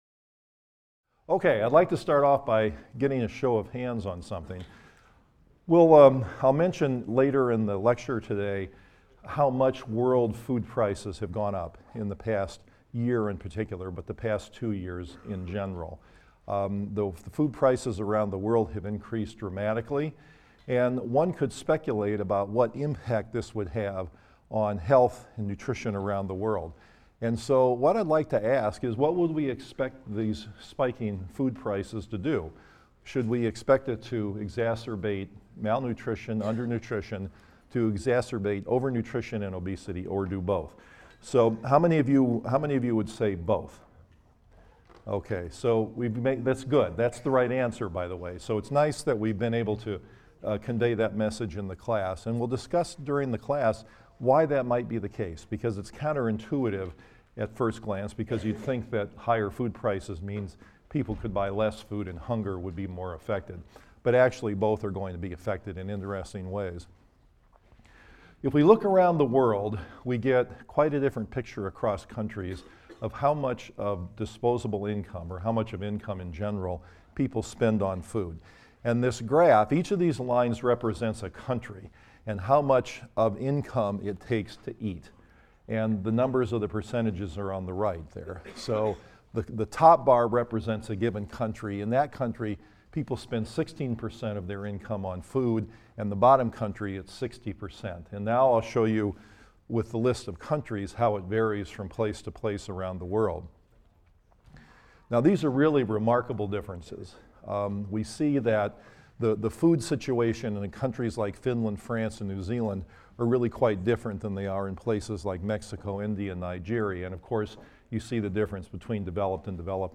PSYC 123 - Lecture 15 - Economics, Nutrition and Health: Subsidies, Food Deserts and More | Open Yale Courses